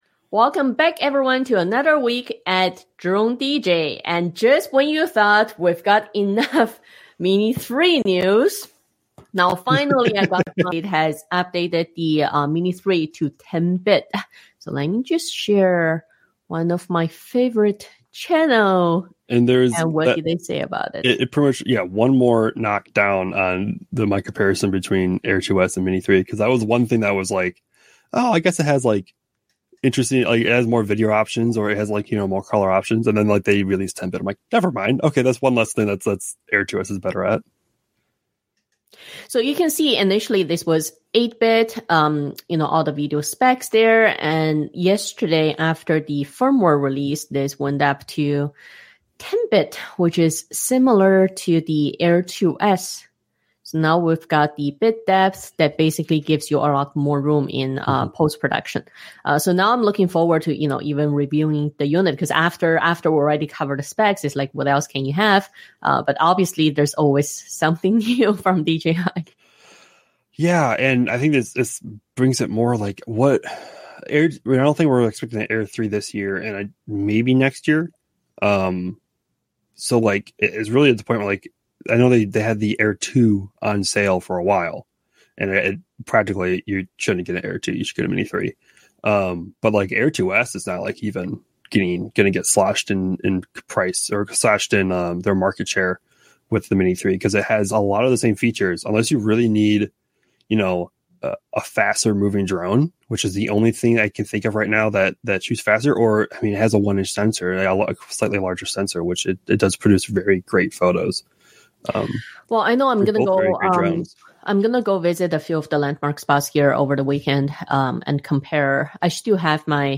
New episodes of the weekly news round-up are recorded live on Friday mornings at 10:00 a.m. EST. You can watch these episodes on DroneDJ’s YouTube channel.